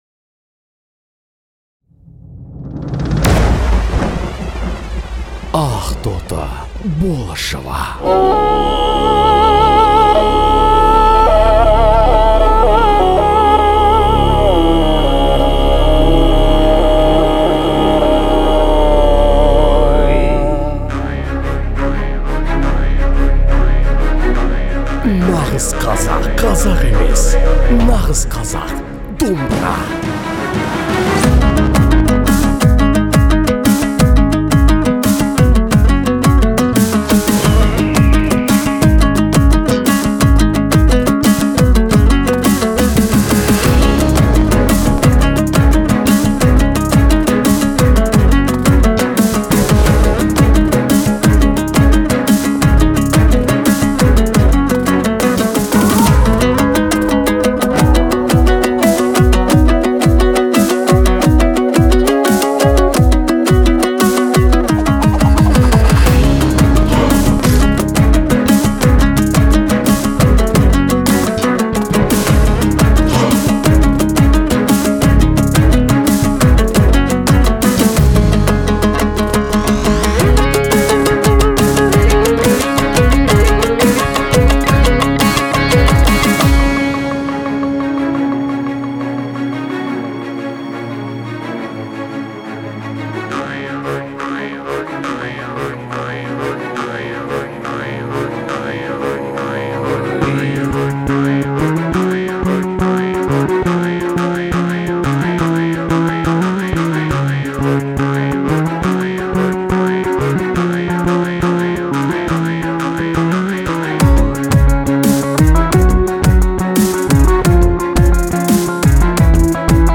исполняет трек с мощной эмоциональной нагрузкой